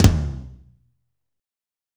Index of /90_sSampleCDs/Northstar - Drumscapes Roland/DRM_Medium Rock/KIT_M_R Kit 2 x
TOM M R L0ZL.wav